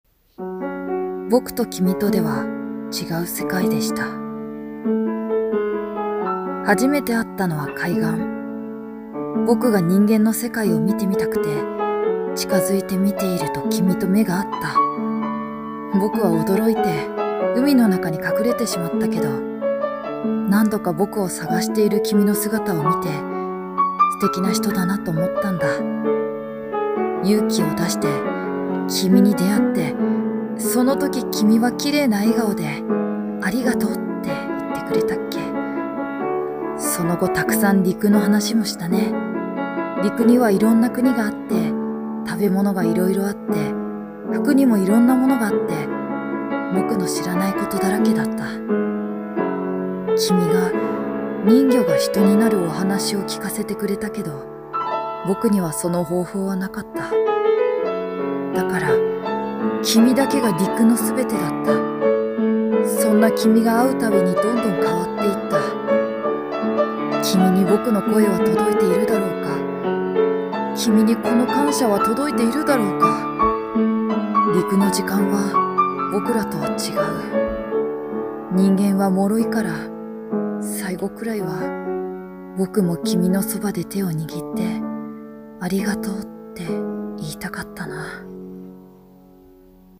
【声劇】 人魚の恋